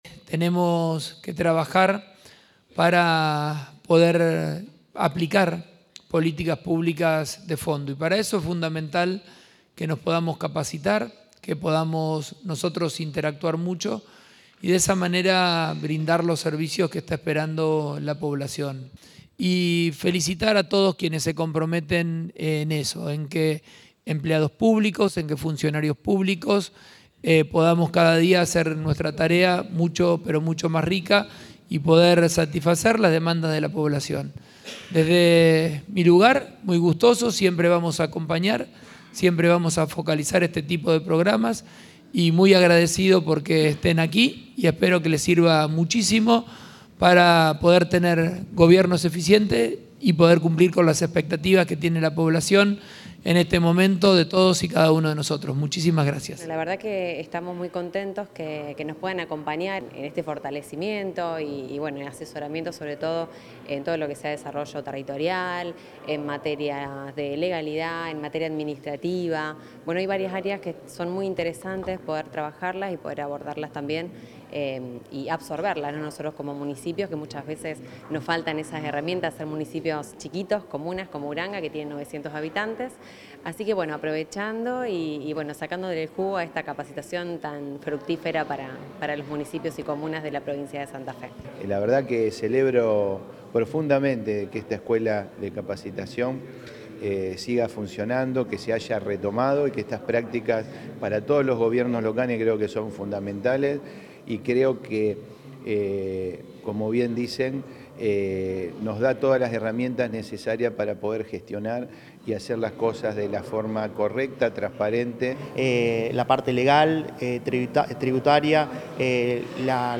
En la Sala Walsh de la sede de Gobierno rosarina, el Gobernador agradeció a los representantes de municipios y comunas “por tomar en serio esta propuesta que hace el gobierno de la provincia de Santa Fe, que tiene que ver con la planificación, la capacitación, pero fundamentalmente tiene que ver con la eficiencia que tenemos que tener en los diferentes gobiernos para brindar mejores servicios” y “llevar adelante políticas de fondo”.
Declaraciones Pullaro